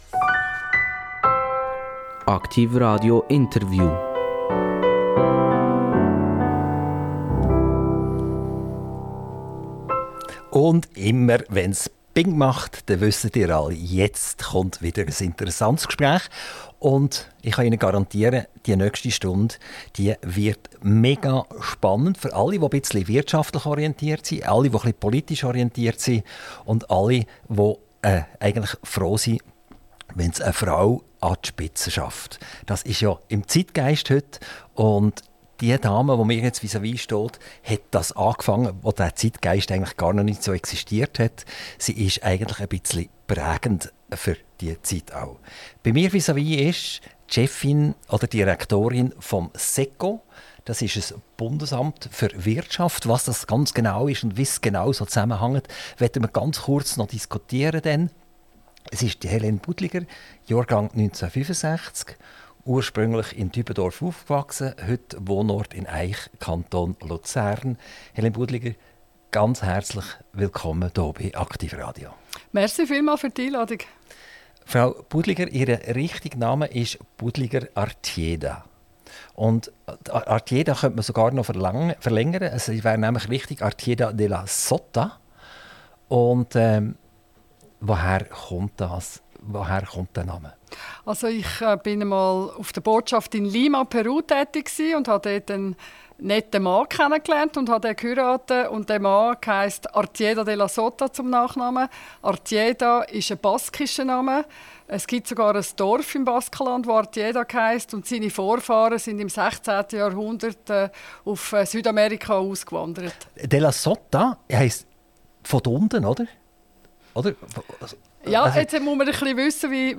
INTERVIEW - Helene Budliger Artieda - 11.03.2024